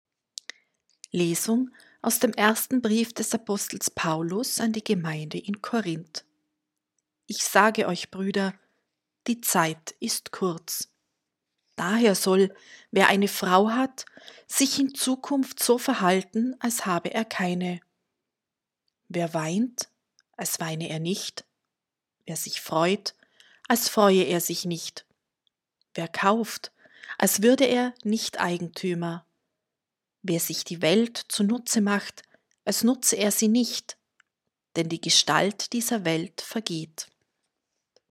Wenn Sie den Text der 2. Lesung aus dem ersten Brief des Apostel Paulus an die Gemeinde in Korinth anhören möchten: